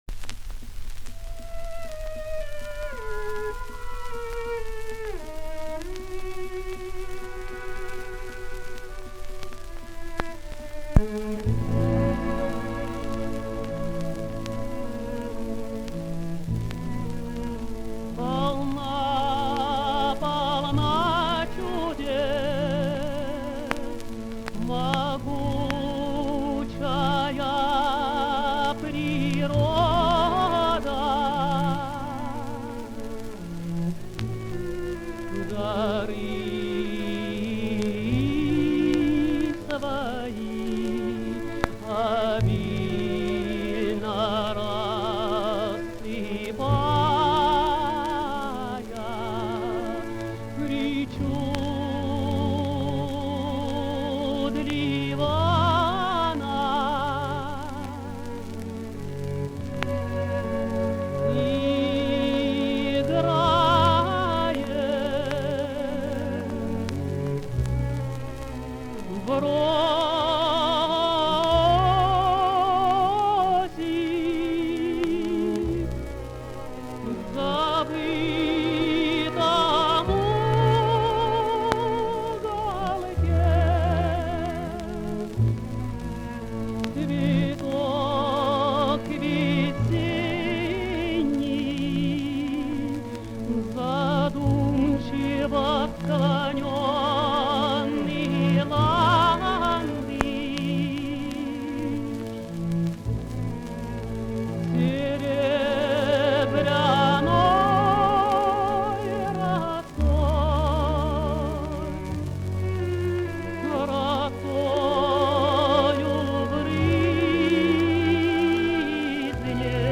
Обладал красивым, «полётным», свободно звучащим голосом, особенно в верхнем регистре. Исполнение отличалось тонкой нюансировкой, мягкой лирической задушевностью.
Каватина Берендея. Оркестр Большого театра.
Исполняет И. Д. Жадан.